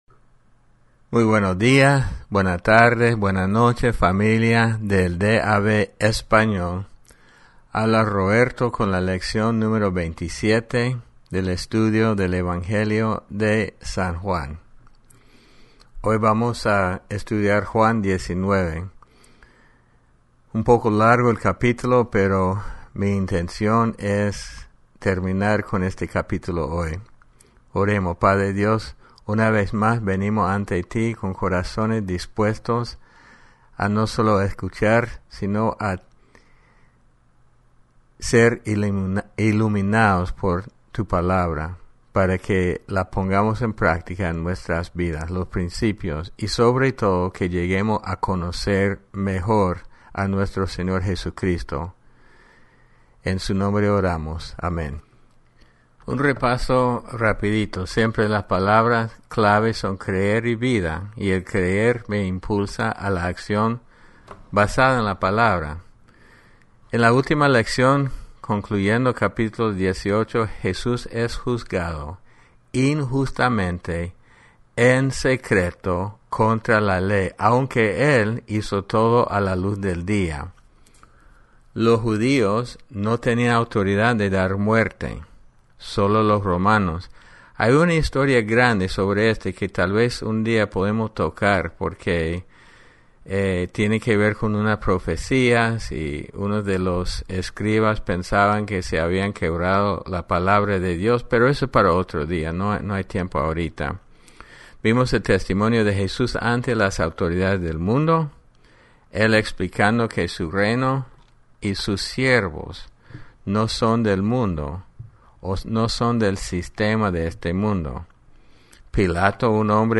Lección 27 El Evangelio de San Juan